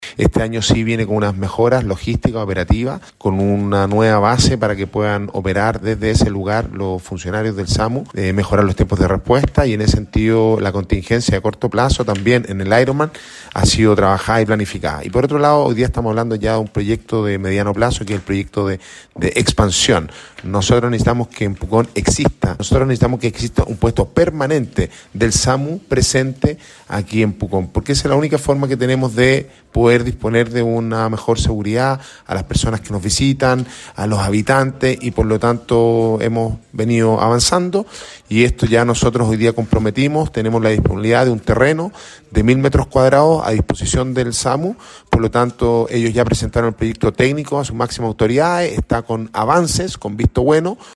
El alcalde de Pucón, Sebastián Álvarez, valoró el avance logrado tras un año de trabajo junto con el SAMU Regional.
Alcalde-Sebastian-Alvarez-mejoras-en-el-sistema-de-salud-de-urgencia-en-la-comuna-copia.mp3